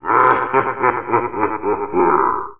laugh1.wav